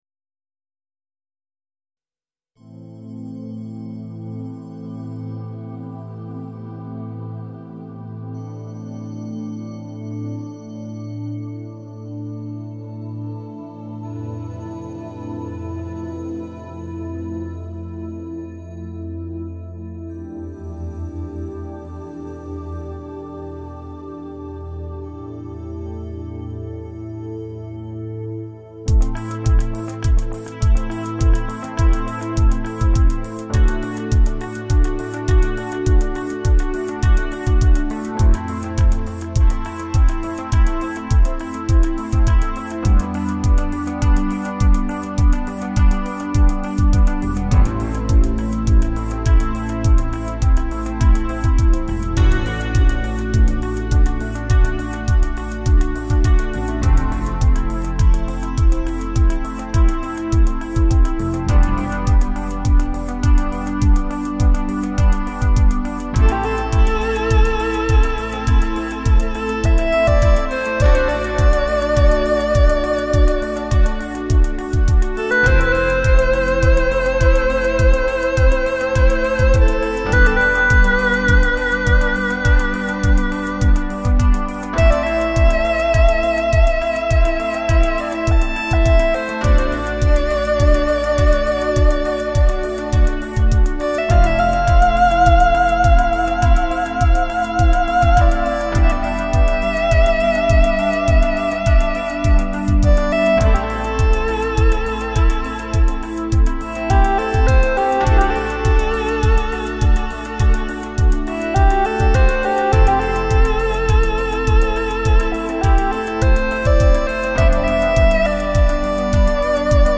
小提琴 Violin
大提琴 Cello
竹笛 Bamboo Flute
竹笛、胡琴在电子乐中轻盈共舞，纯粹的色泽，珐琅丝般的精巧，投影古典